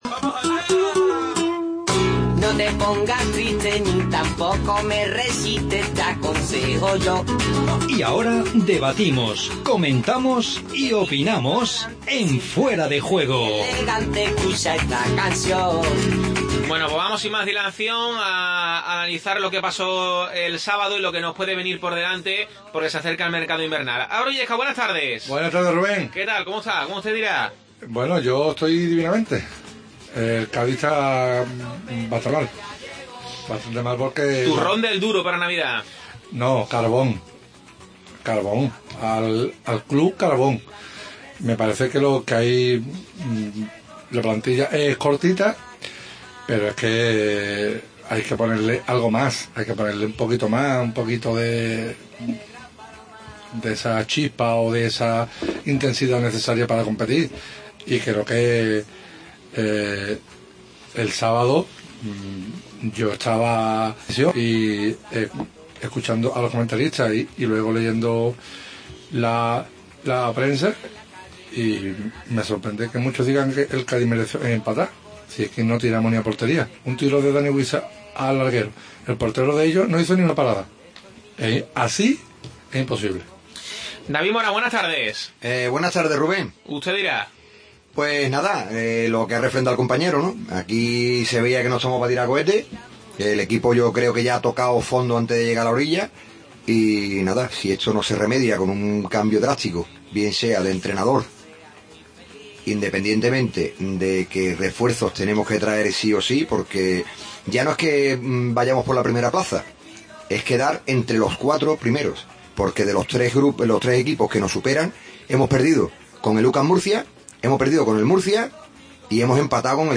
AUDIO: Debate En Fuera de Juego y escuchamos los 10 sonidos de 2015